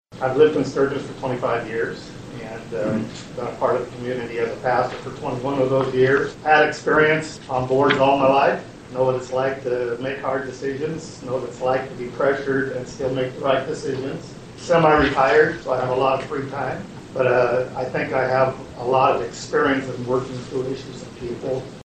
City Commission Interviews Candidates For 2nd Precinct Seat